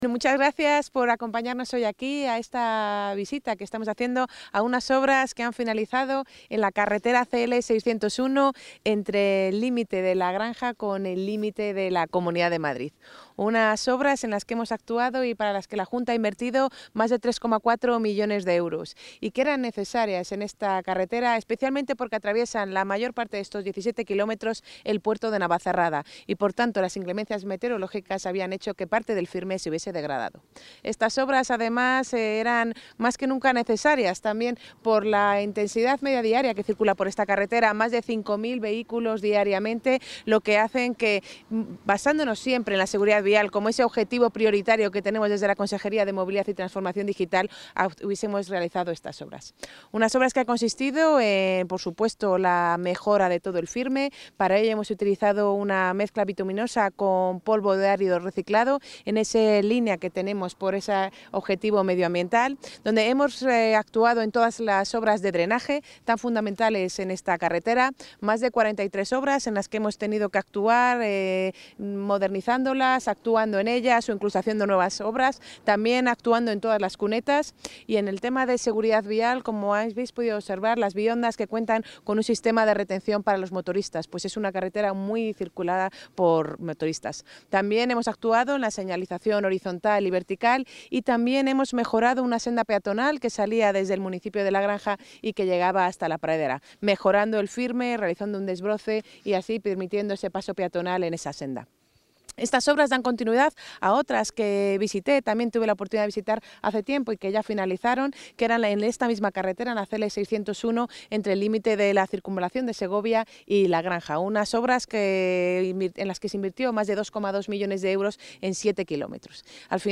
Intervención de la consejera.